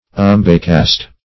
Search Result for " umbecast" : The Collaborative International Dictionary of English v.0.48: Umbecast \Um"be*cast`\, v. i. [Umbe + cast.] To cast about; to consider; to ponder.